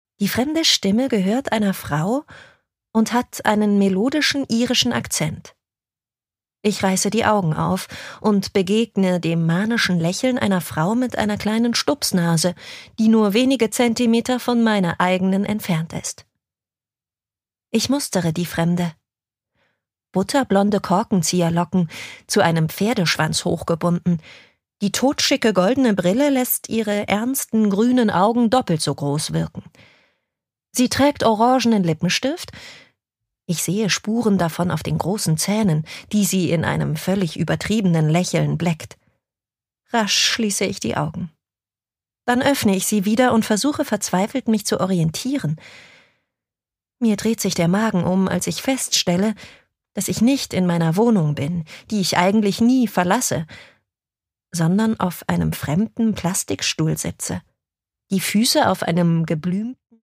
Produkttyp: Hörbuch-Download
MP3 Hörbuch-Download